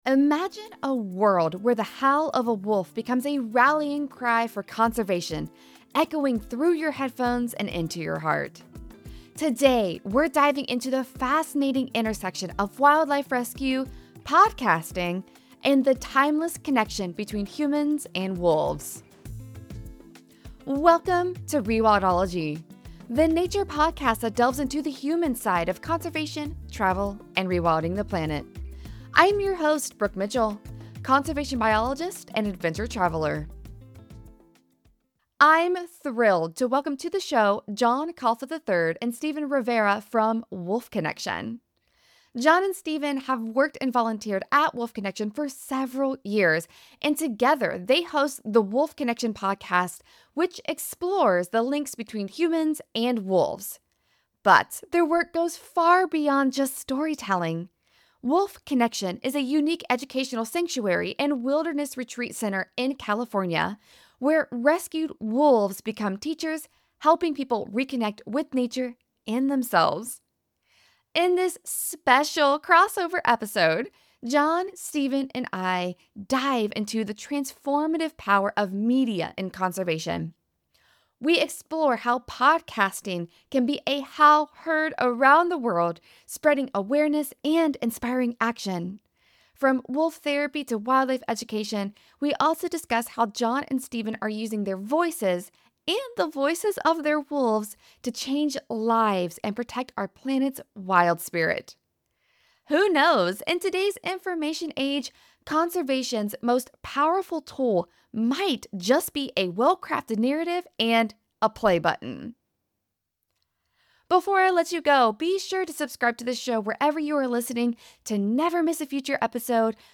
In this special crossover episode, Rewildology teams up with the hosts of the Wolf Connection Podcast to explore the intersection of wildlife conservation and digital media.